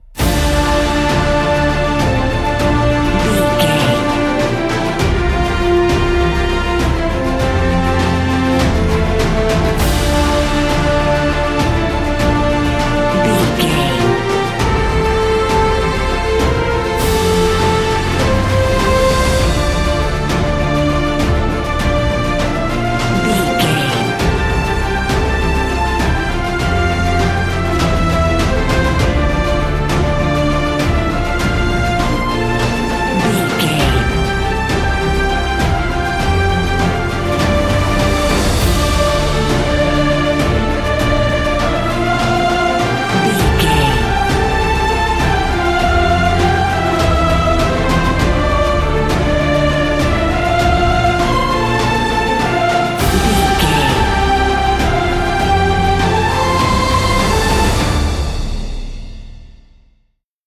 Epic / Action
Uplifting
Aeolian/Minor
energetic
powerful
brass
drums
strings